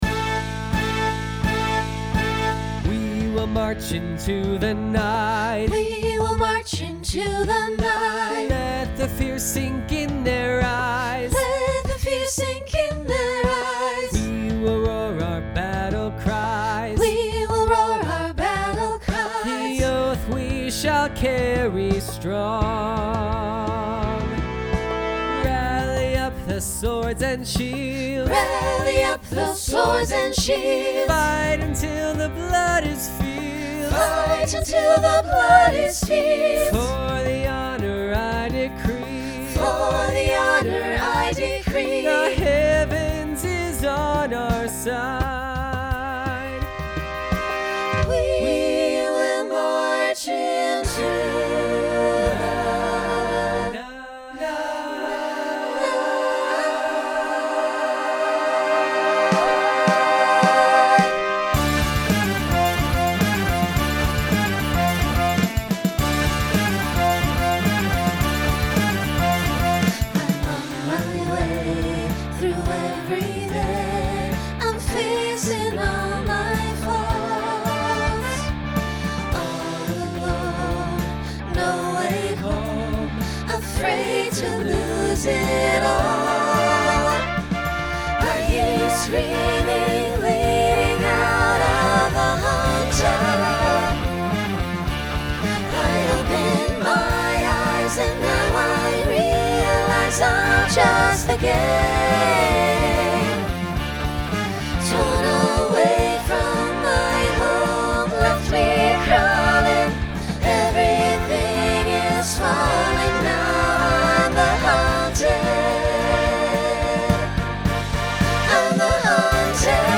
Genre Rock Instrumental combo
Story/Theme Voicing SATB